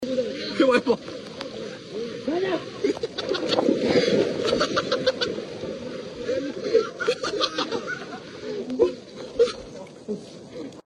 Two Much Flooded In Korea Sound Effects Free Download